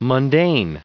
added pronounciation and merriam webster audio
521_mundane.ogg